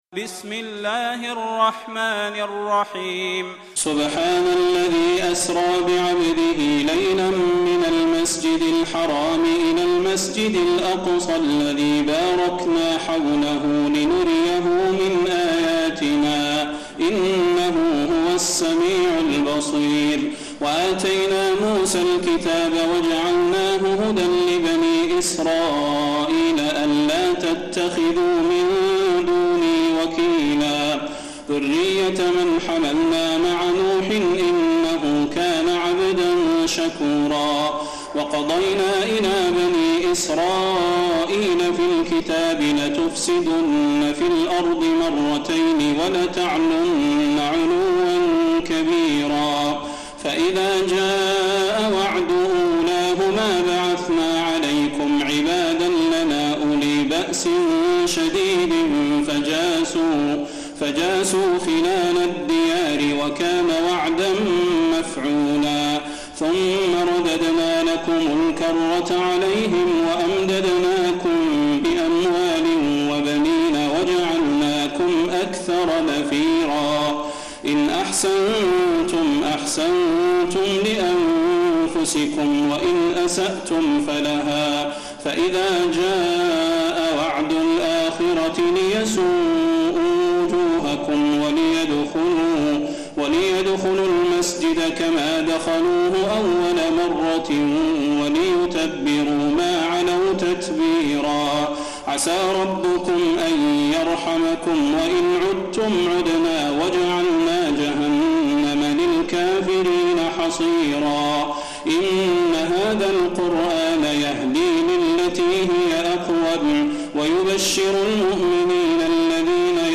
تراويح الليلة الرابعة عشر رمضان 1432هـ سورة الإسراء Taraweeh 14 st night Ramadan 1432H from Surah Al-Israa > تراويح الحرم النبوي عام 1432 🕌 > التراويح - تلاوات الحرمين